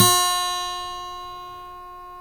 GTR 6-STR20Y.wav